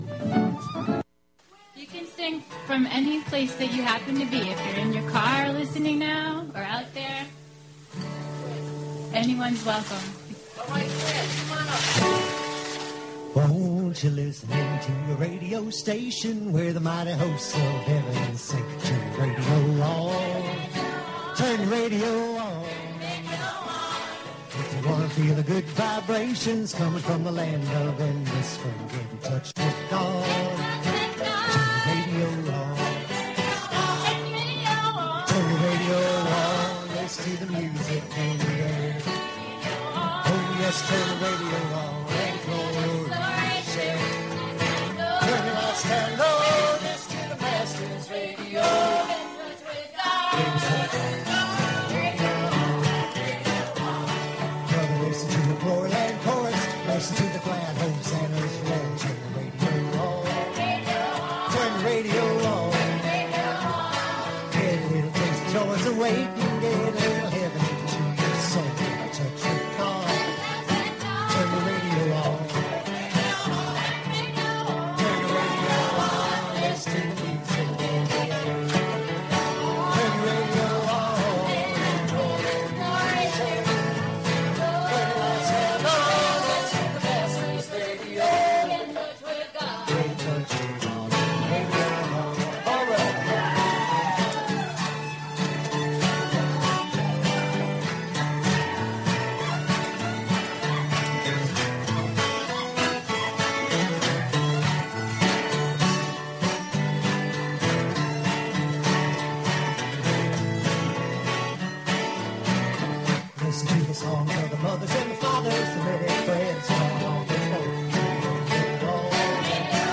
Special broadcast of live performances from Spotty...